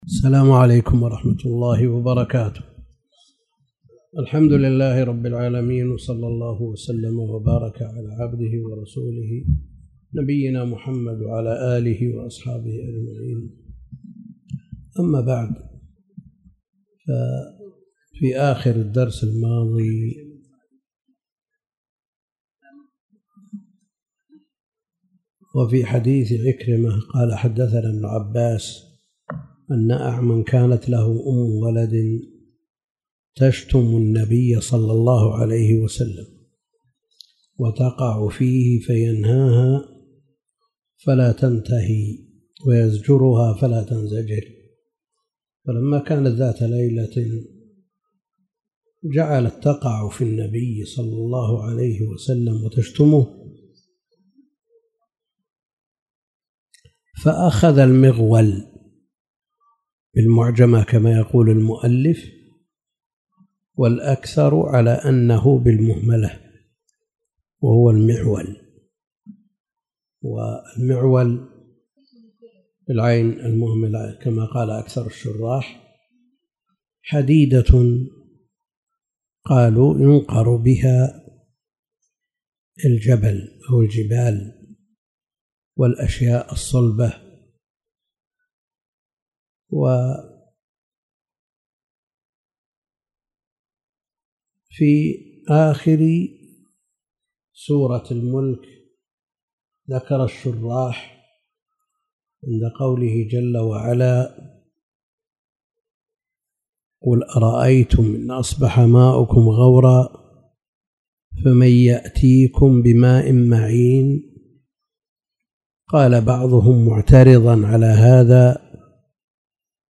تاريخ النشر ٢٥ ربيع الأول ١٤٣٨ المكان: المسجد الحرام الشيخ: فضيلة الشيخ د. عبد الكريم بن عبد الله الخضير فضيلة الشيخ د. عبد الكريم بن عبد الله الخضير كتاب الحدود